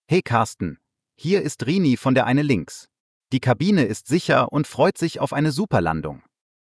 CallCabinSecureLanding.ogg